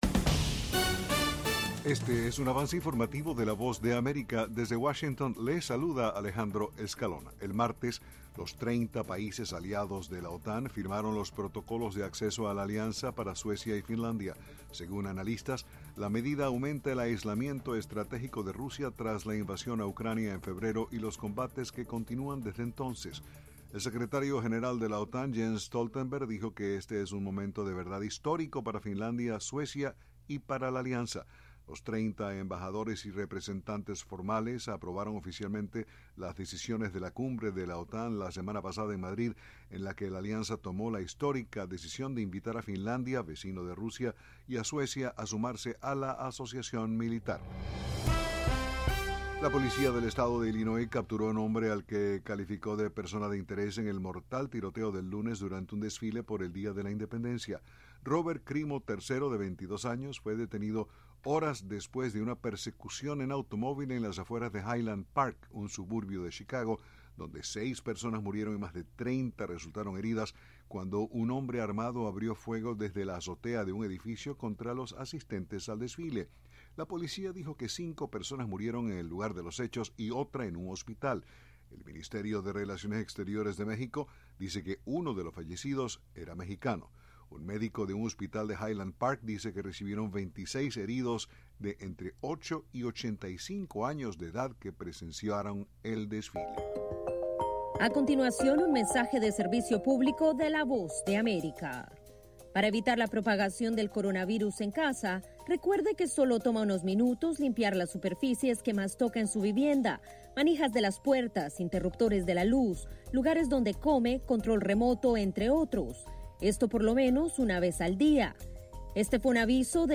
El siguiente es un avance informativo presentado por la Voz de América, Washington.